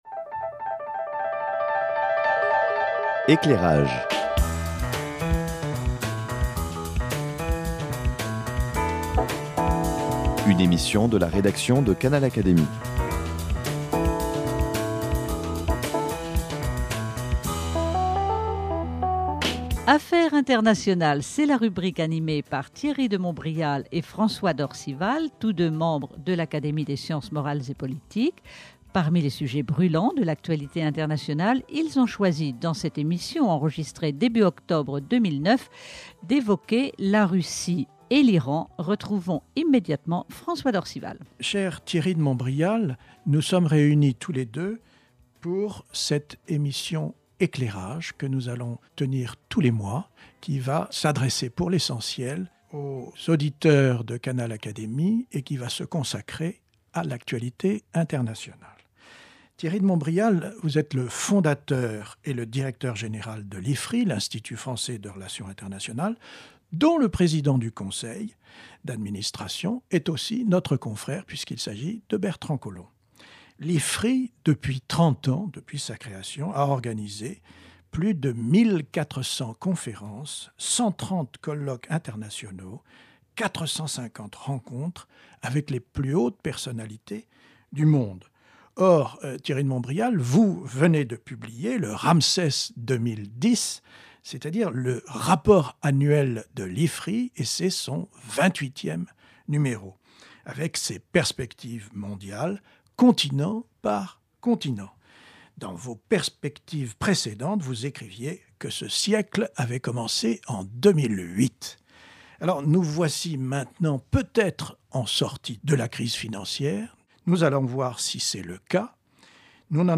Dans ce premier numéro, Thierry de Montbrial, directeur de l’Institut français de relations internationales IFRI, dialogue avec François d’Orcival, journaliste et éditorialiste, à propos des ambitions de la Russie et de la stratégie de Barack Obama.